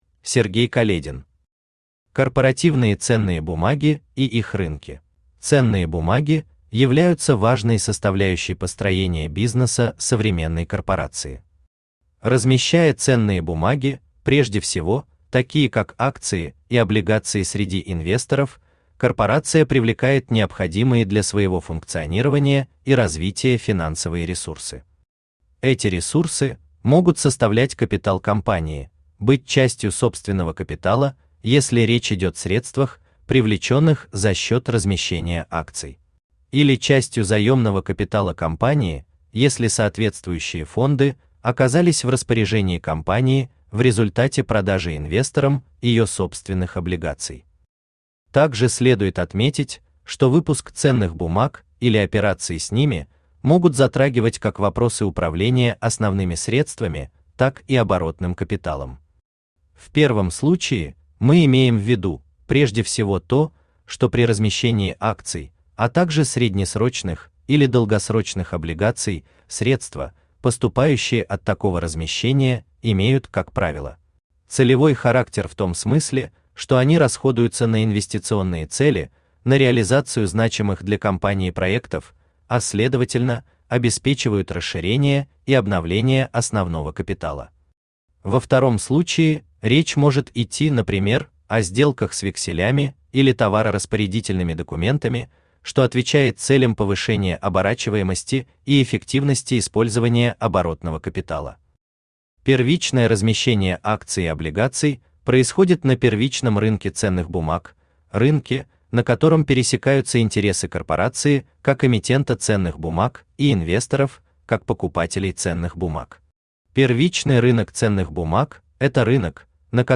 Aудиокнига Корпоративные ценные бумаги и их рынки Автор Сергей Каледин Читает аудиокнигу Авточтец ЛитРес. Прослушать и бесплатно скачать фрагмент аудиокниги